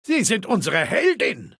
Datei:Maleold01 ms06fin hello 00049e26.ogg
Fallout 3: Audiodialoge